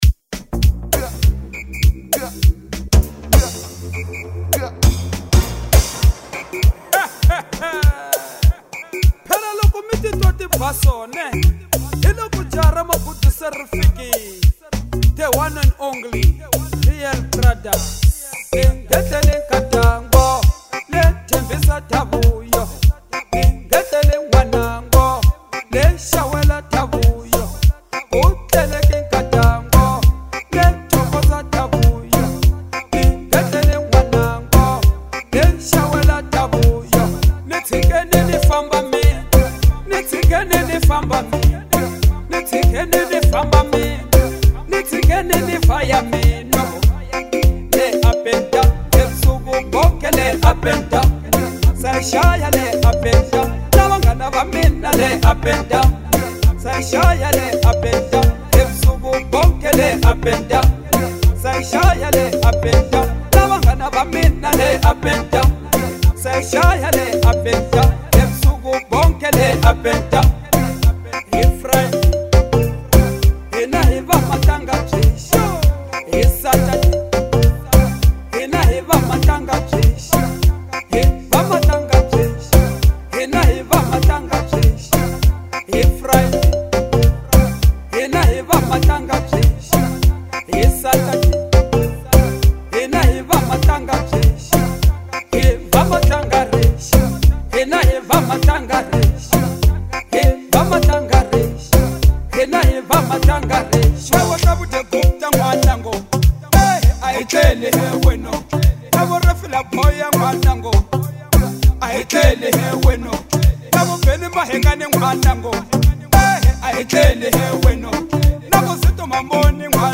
| Afro Classic